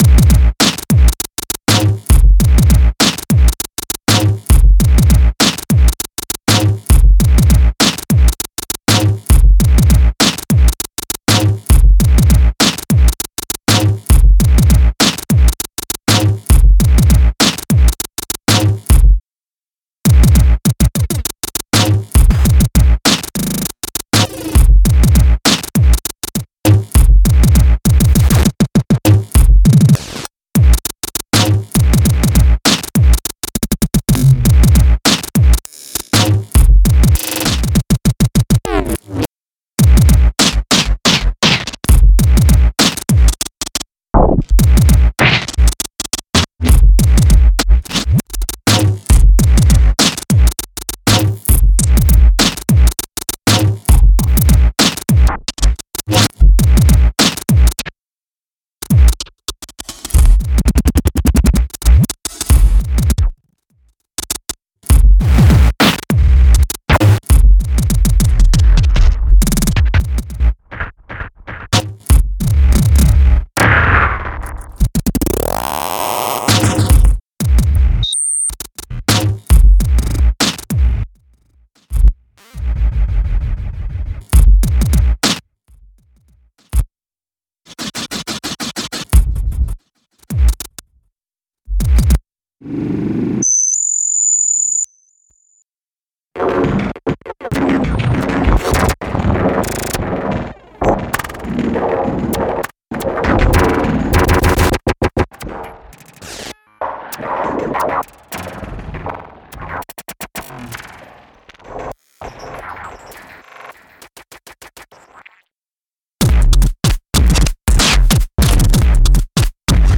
Beat Programming
Funkstörung machen’s vor: Ausgangspunkt ist ein relativ bodenständiger 100-BPM-Beat im HipHop-ähnlichen BreakbeatStyle. Er wird in mehreren Schritten heftig mit klanglichen und rhythmischen Effekten bearbeitet und neu zusammengesetzt. Das Ergebnis ist ein überaus aggressiv polternder Stolper-Beat mit reichlich Dreck und Biss.